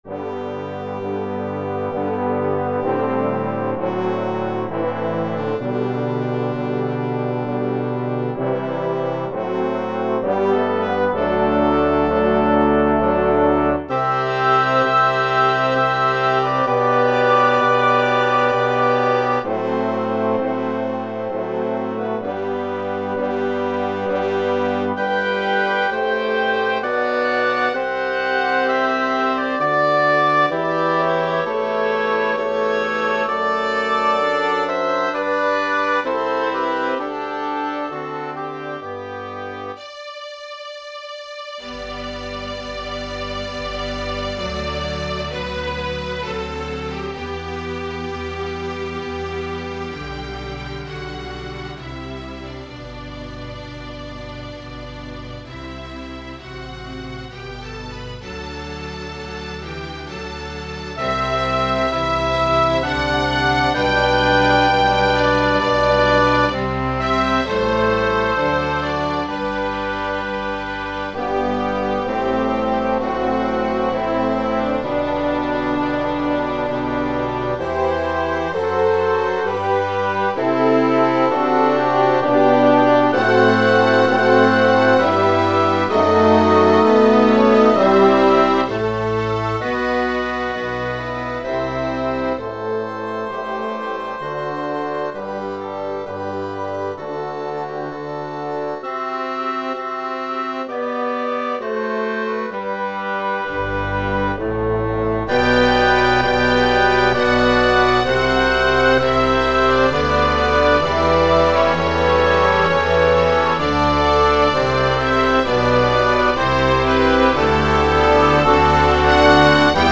Largo From Xerxes for Full Orchestra
I posted this for band in the key of F, but this arrangement for orchestra is in the original key of G. I used the FINALE program for the score and parts as well as the recording.
CLASSICAL MUSIC